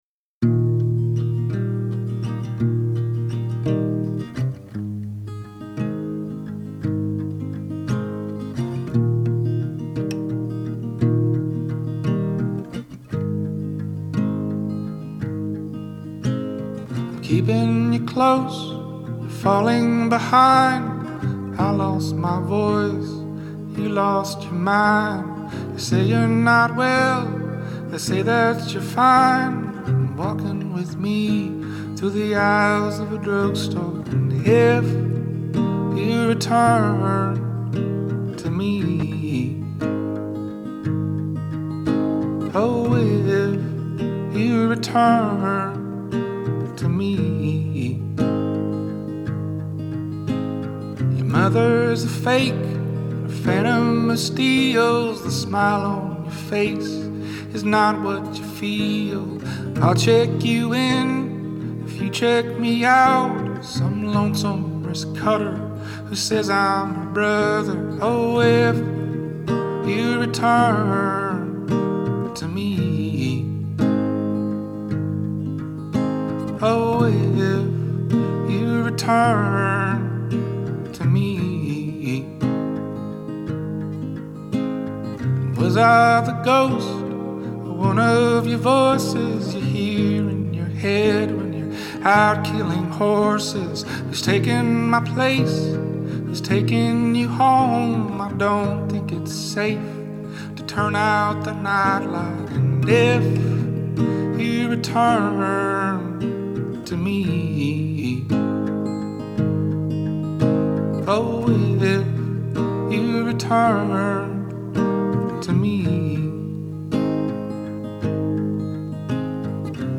cover
the warmed-up, knowing rasp